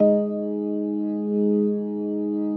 B3LESLIE G#3.wav